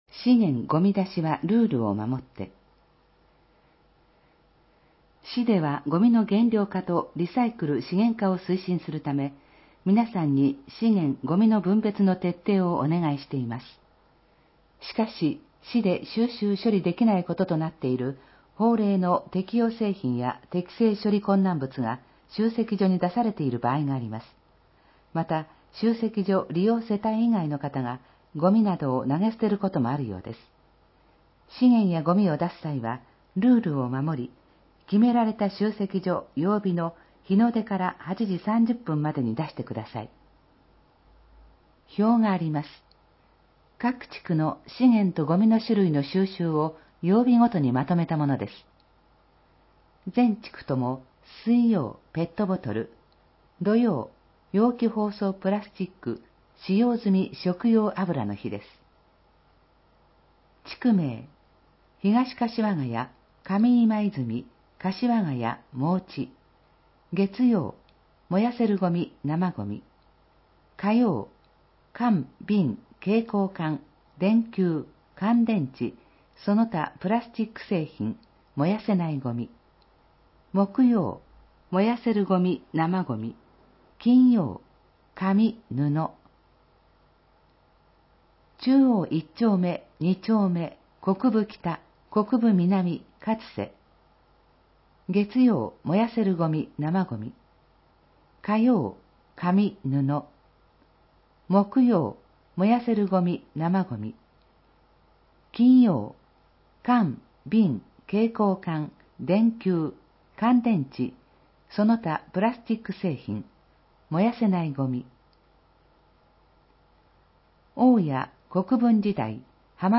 音声版は、音声訳ボランティア「矢ぐるまの会」のご協力により、同会が視覚障がい者の方のために作成したものを登載しています